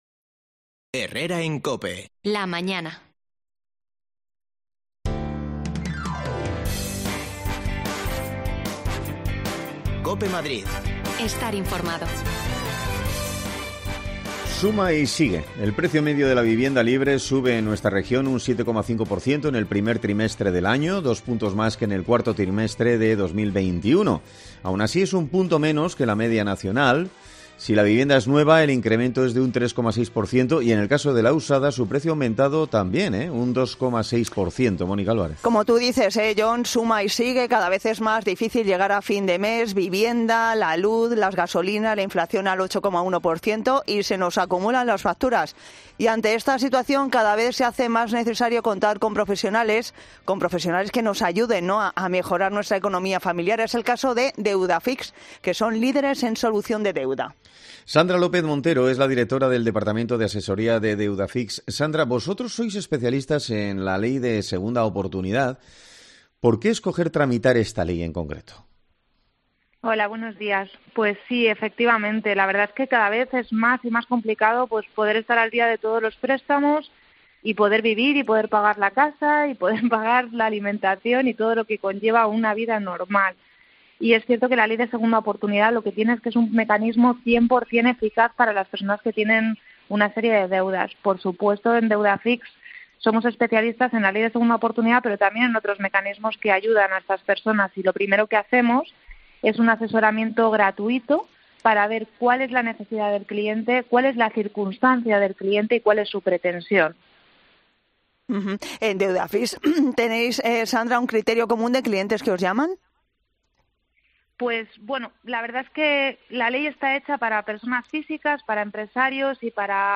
Los pisos turísiticos siguen siendo un quebradero de cabeza para los vecinos de muchos barrios céntricos de la capital. Nos acercamos al barrio de las letras para escucharles
Las desconexiones locales de Madrid son espacios de 10 minutos de duración que se emiten en COPE , de lunes a viernes.